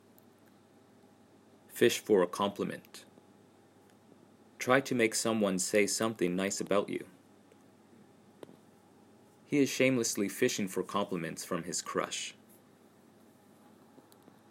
英語ネイティブによる発音は下記のリンクをクリックしてください。
fishforacompliment.mp3